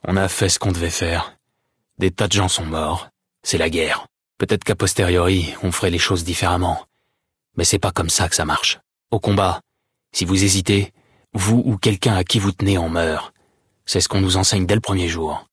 Catégorie:Dialogue audio de Fallout: New Vegas